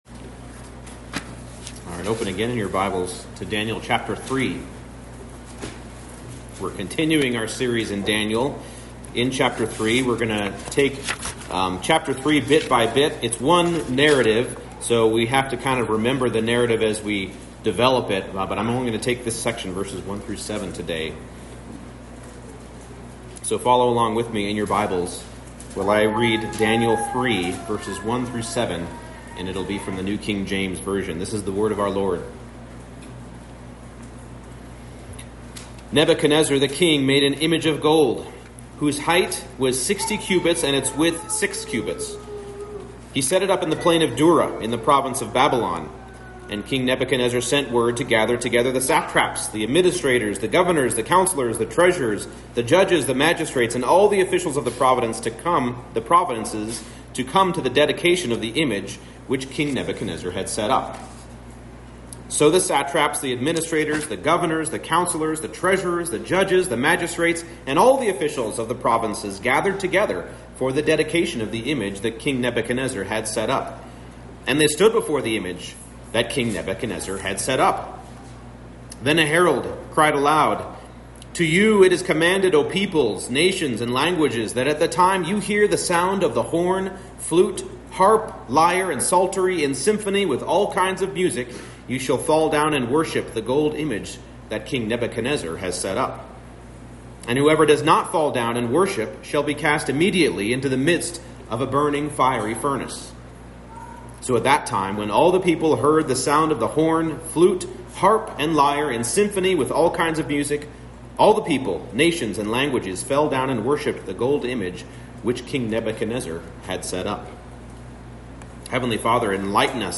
Daniel 3:1-7 Service Type: Morning Service Knowledge and authority belong to God